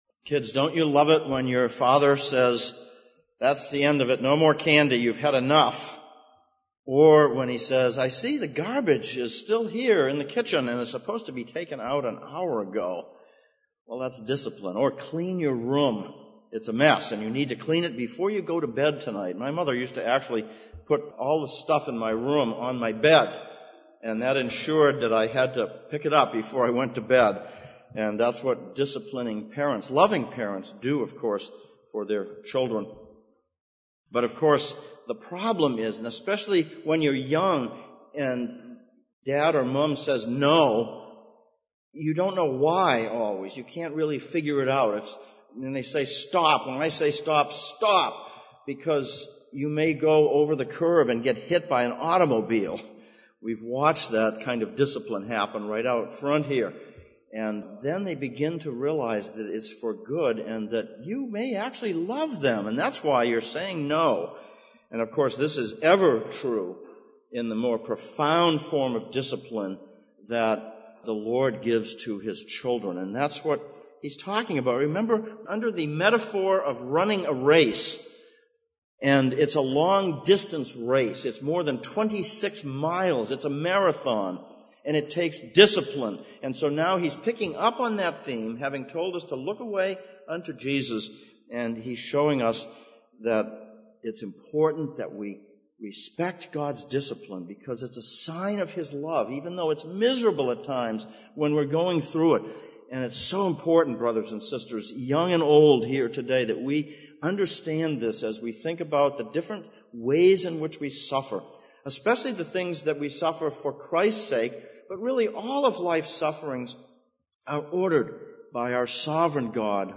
Hebrews Passage: Hebrews 12:3-17, Proverbs 3:1-12 Service Type: Sunday Morning « Unfathomable Love Be Afraid